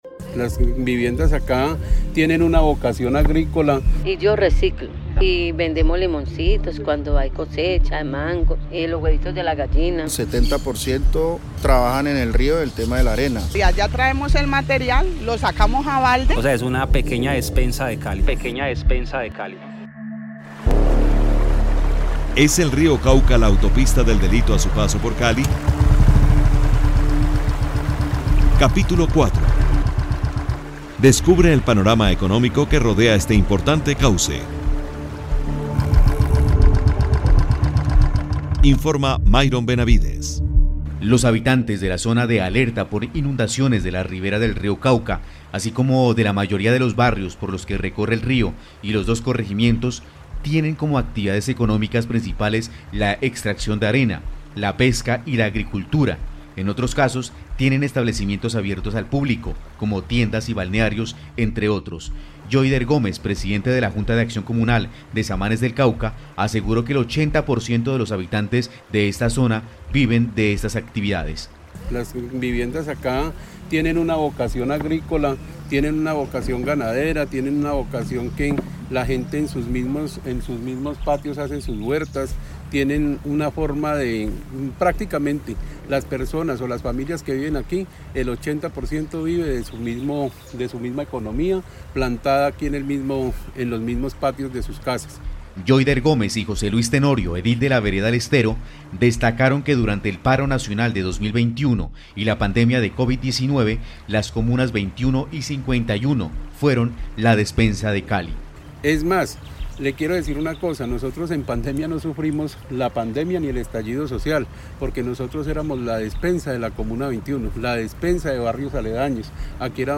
Pasado y presente del río Cauca, con testimonios de la realidad del más importante cauce que cruza a Cali.